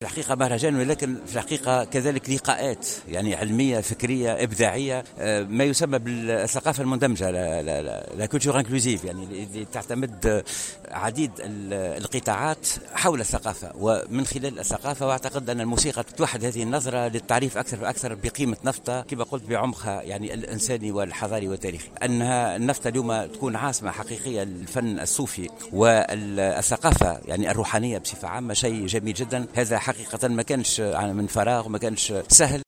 ولاحظ الوزير، في تصريح اعلامي بالمناسبة، أن غياب الفضاءات الثقافية في ولاية توزر يعود الى تعطل المشاريع في هذا القطاع بسبب طول الإجراءات مشيرا الى انه تم عقد جلسة عمل عشية الخميس في مقر الولاية خصصت للنظر في اسباب تعطل هذه المشاريع مشددا على ضرورة إعادة استغلال العديد من الفضاءات في المدن وتحويلها الى فضاءات ثقافية.